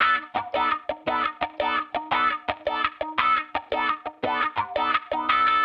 Index of /musicradar/sampled-funk-soul-samples/85bpm/Guitar
SSF_StratGuitarProc2_85B.wav